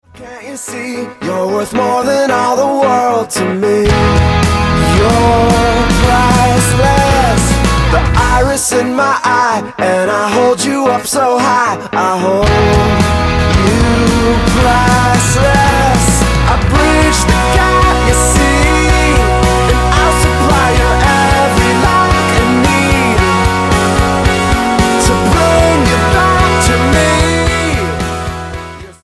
Pop rockers